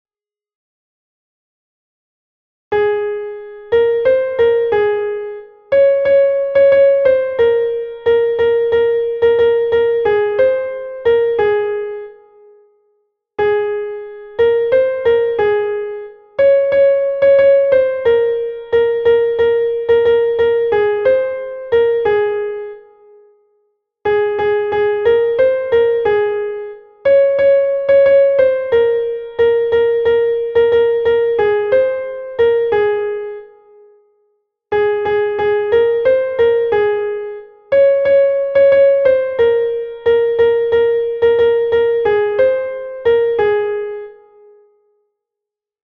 Chant Commun.
Soprano
Tchotsholoza-Soprano.mp3